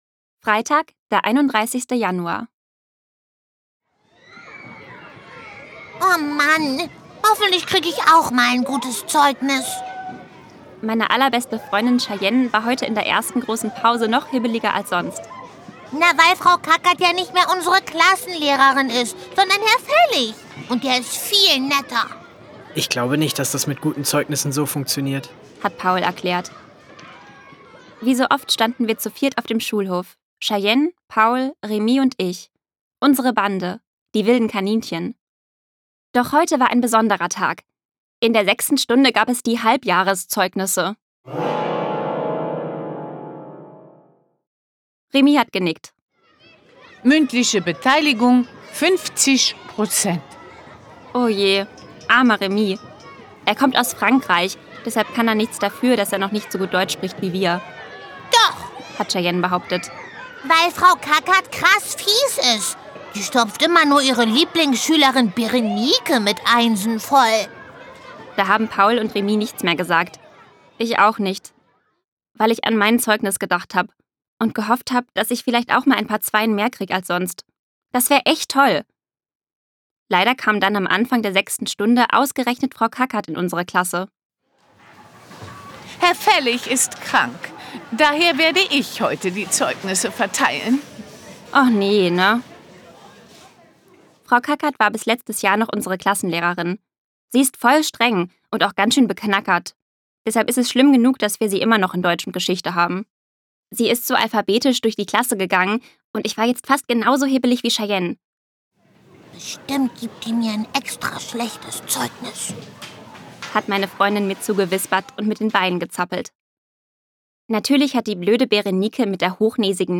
ungekürzte Lesung
Es gibt viele Geräusche, die die jeweilige Situation untermalen.